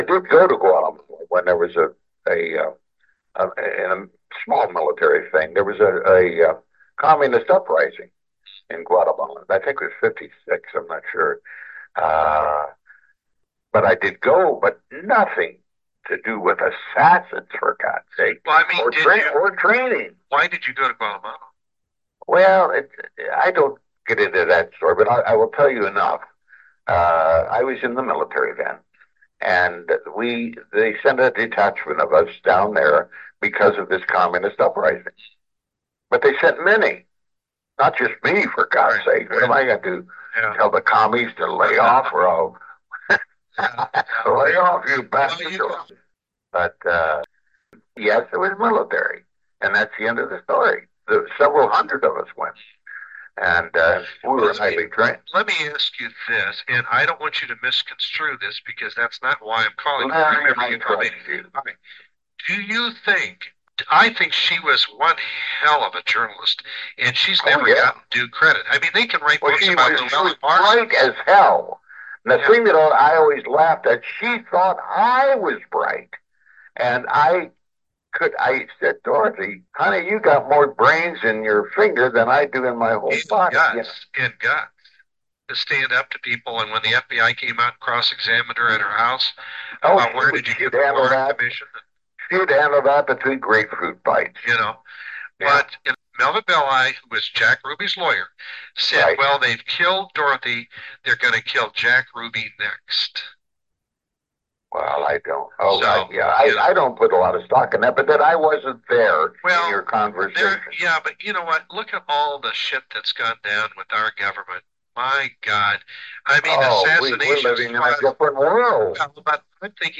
Excerpts of exclusive interviews with the man whom some suspect as